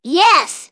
synthetic-wakewords
ovos-tts-plugin-deepponies_Bart Simpson_en.wav